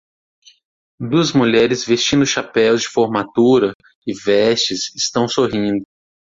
Pronunciado como (IPA)
/foʁ.maˈtu.ɾɐ/